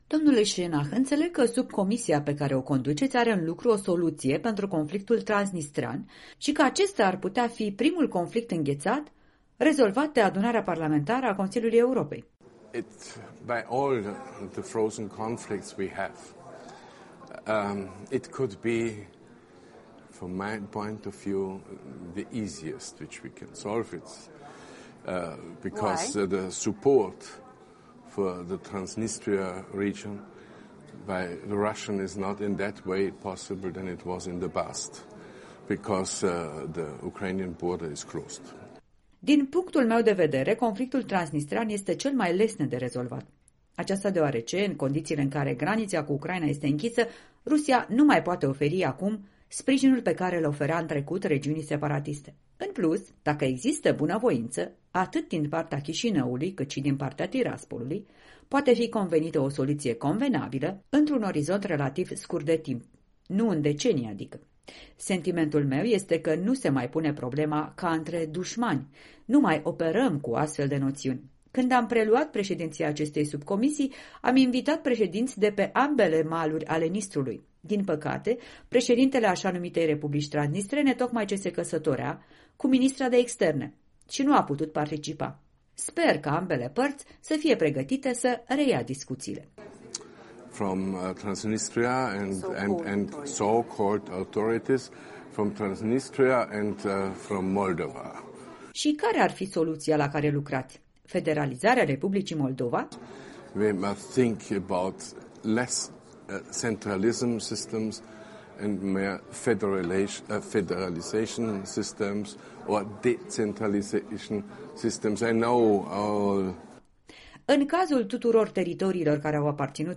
Un interviu cu președintele Subcomisiei APCE pentru conflictele înghețate.
Conflictul transnistrean ar putea fi primul care își va găsi rezolvarea, spune încrezător Schennach oferind și explicații într-un interviu acordat în exclusivitate, la Strasbourg, Europei Libere.